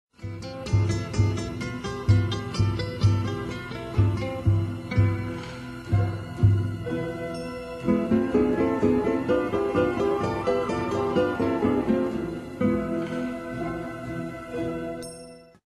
Gitarren mit Orchester